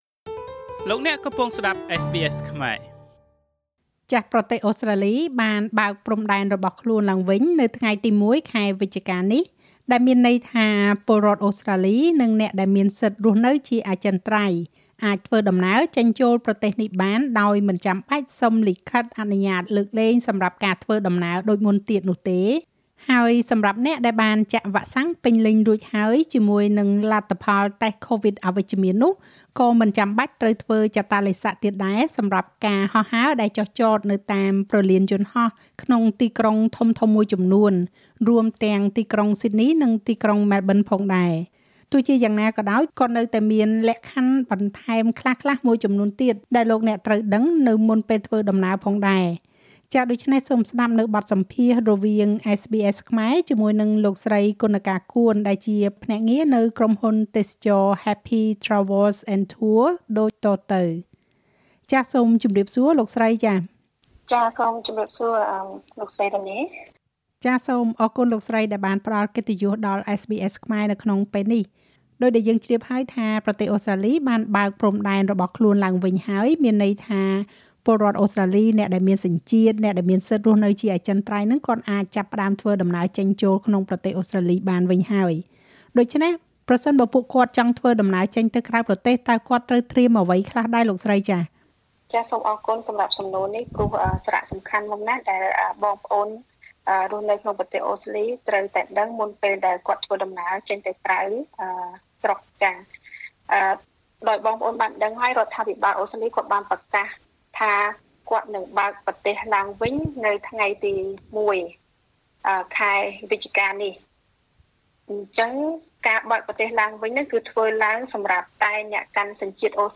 សូមស្តាប់នូវបទសម្ភាសន៍រវាង SBS ខ្មែរ